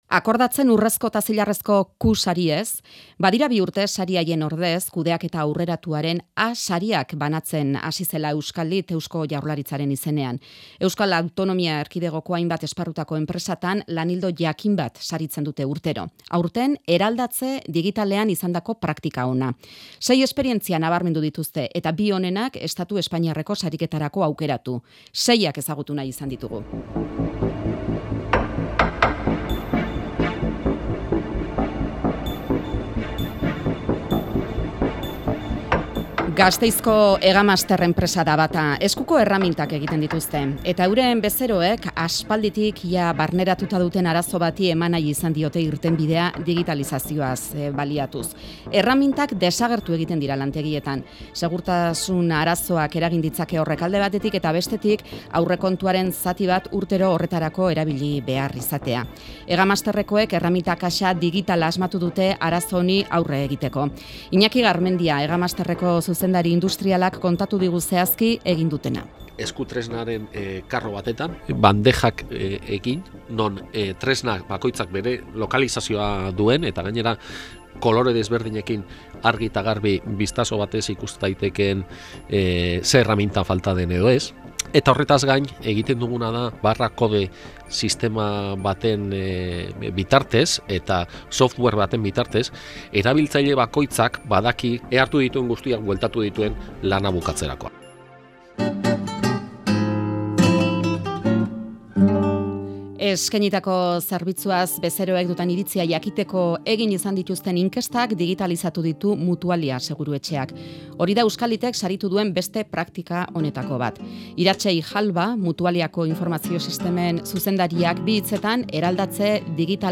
Entrevista en torno a la transformación digital